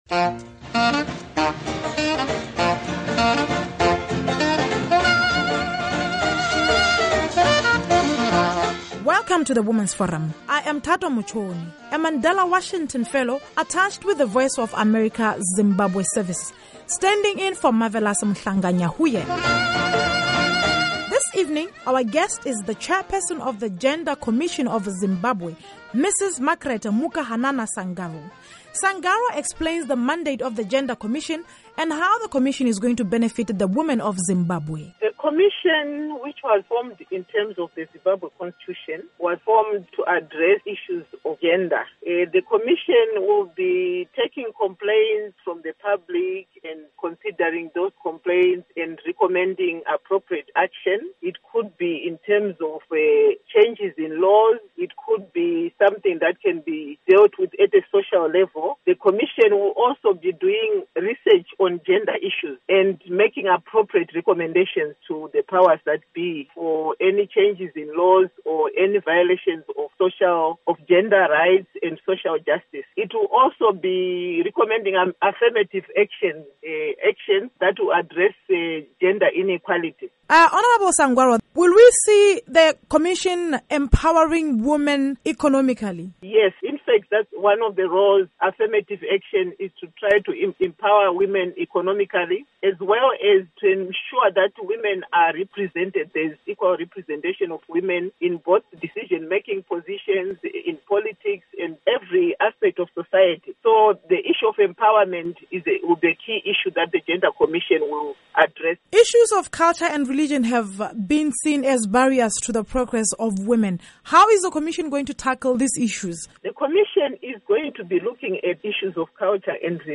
Interview with Zimbabwe Gender Commission Chair - Honorable Mukahanana Sangarwo